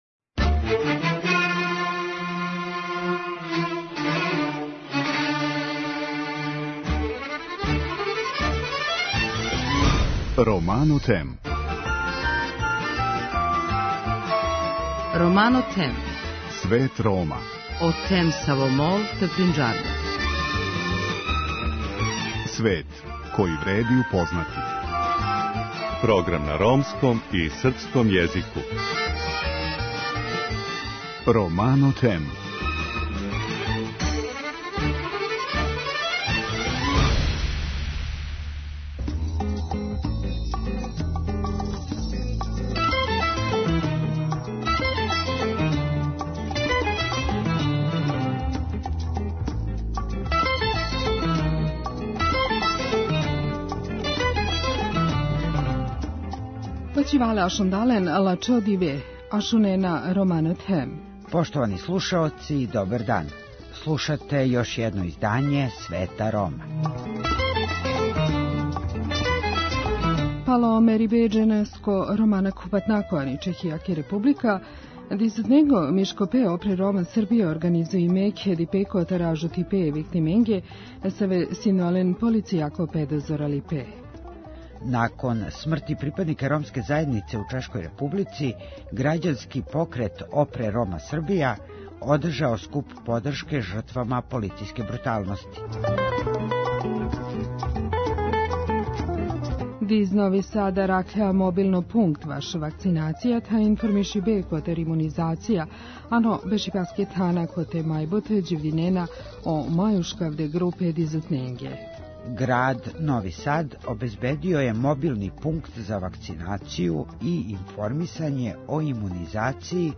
Вести на ромском језику